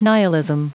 Prononciation du mot nihilism en anglais (fichier audio)
Prononciation du mot : nihilism